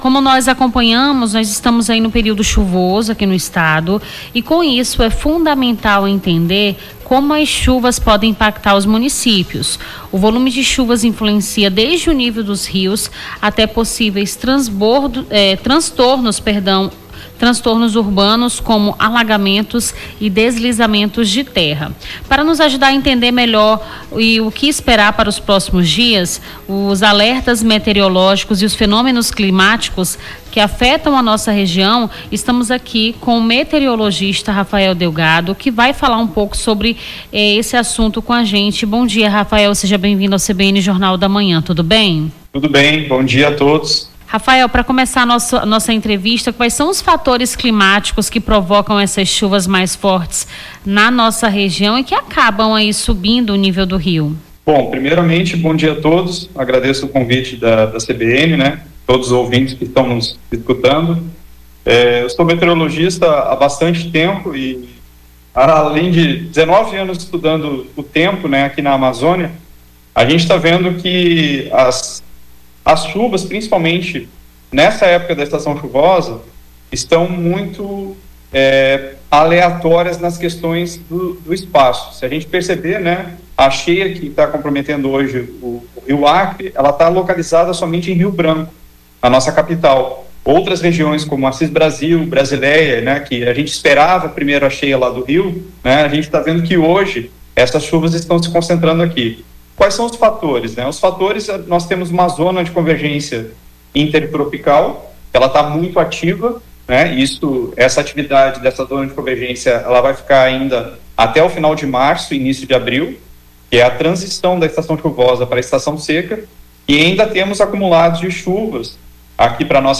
AO VIVO: Confira a Programação
Nome do Artista - CENSURA - ENTREVISTA CHUVAS MARÇO (11-03-25).mp3